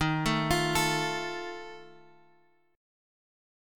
D# Suspended 2nd Flat 5th